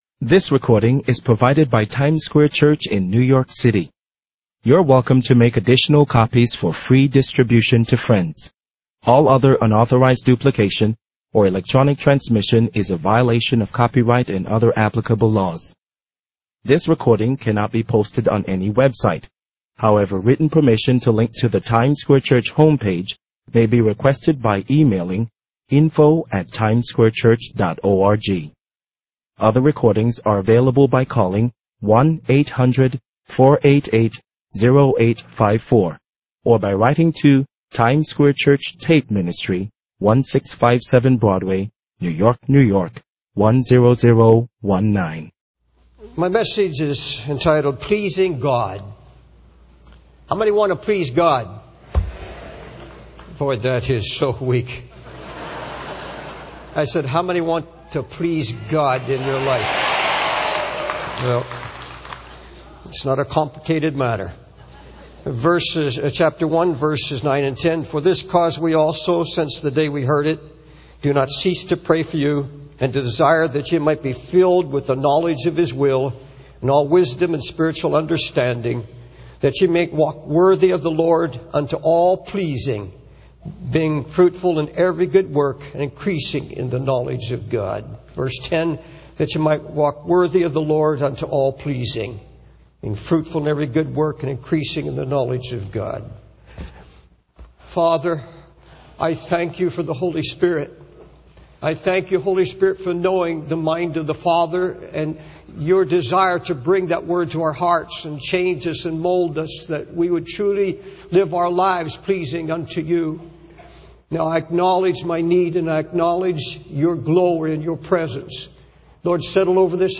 In this sermon, the preacher emphasizes the importance of hope and the mercy of God. He encourages the congregation to trust in God's mercy and to ask Him to teach them how to hope. The preacher also highlights the significance of saving lost souls and shares the parable of the lost sheep from Luke 15.